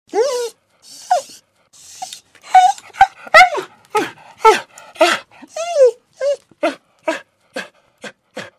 Другие рингтоны по запросу: | Теги: скулит, Собака, пес